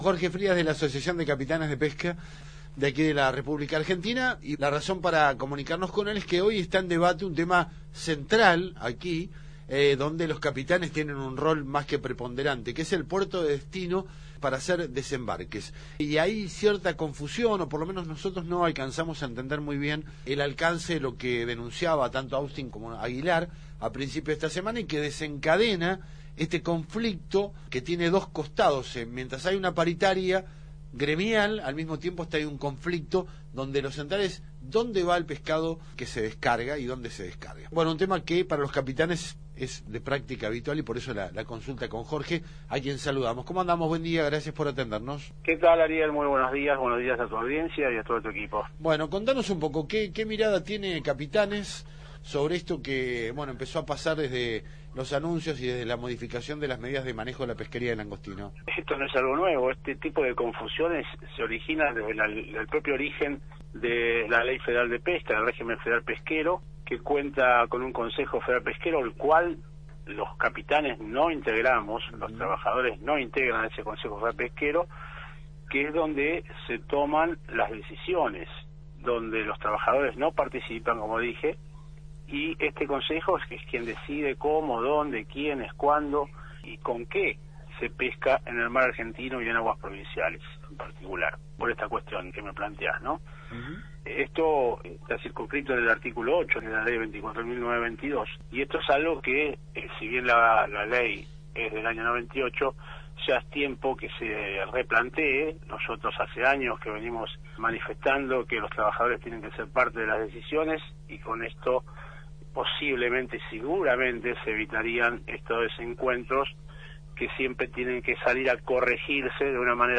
Audio de entrevista
en el programa radial Activemos